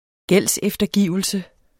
Udtale [ ˈgεlˀsεfdʌˌgiˀvəlsə ]